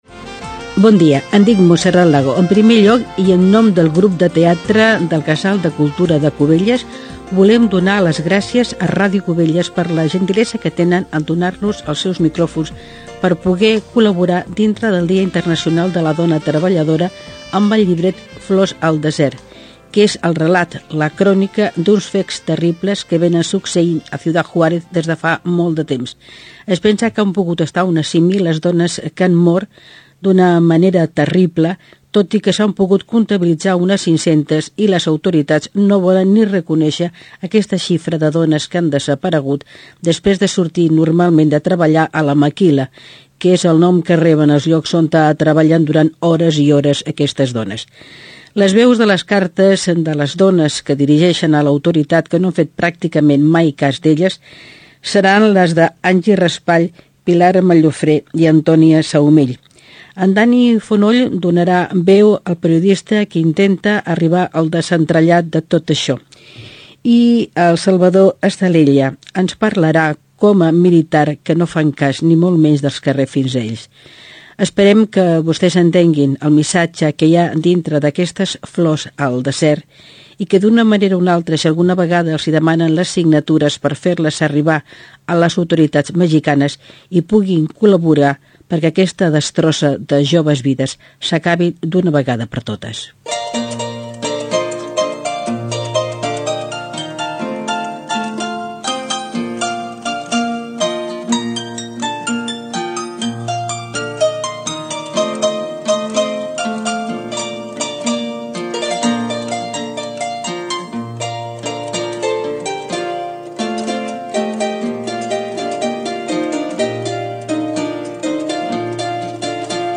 Àudio: arxiu sonor i podcast de Ràdio Vilanova, Ràdio Cubelles i Ràdio Maricel